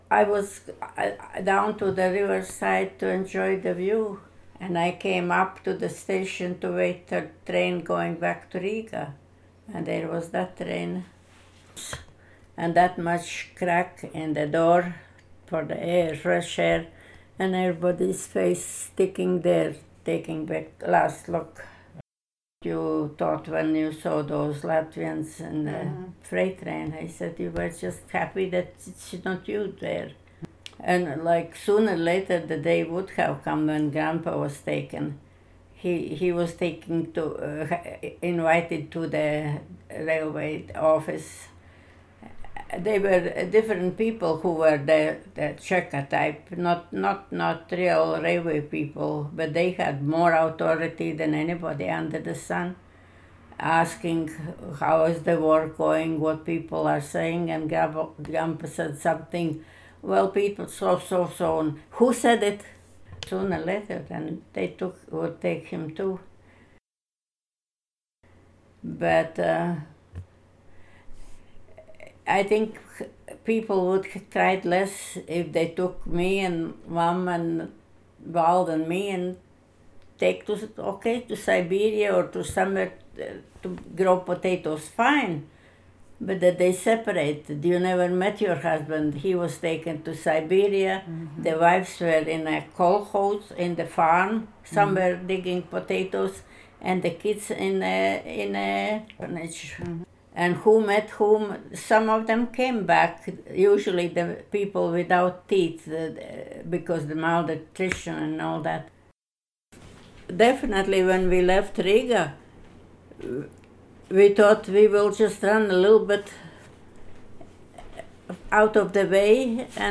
Interviewer (ivr)